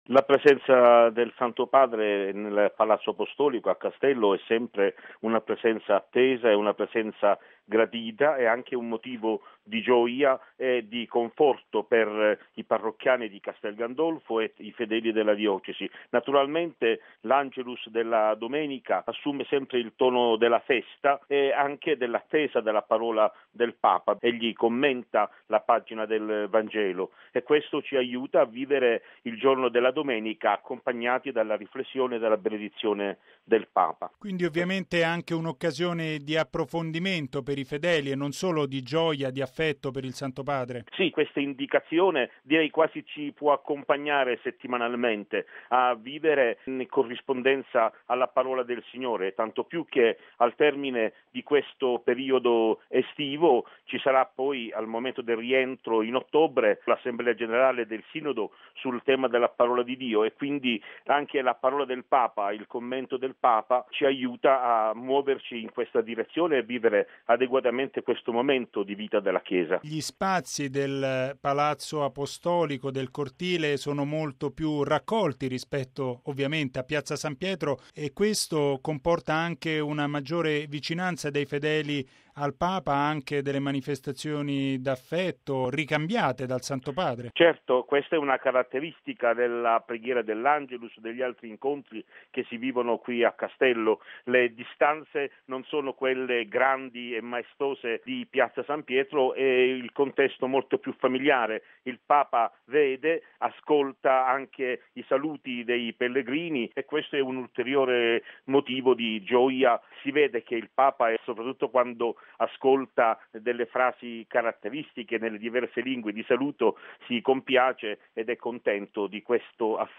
ha intervistato il vescovo di Albano, mons. Marcello Semeraro